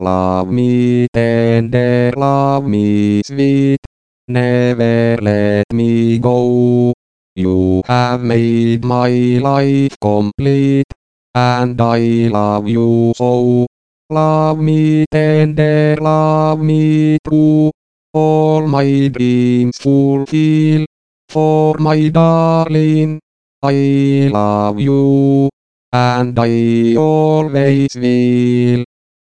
The MikroPuhe speech synthesizer has the ability to sing, and ships with some example song files. One of these is an attempt at singing a song in english